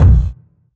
GS Phat Kicks 016.wav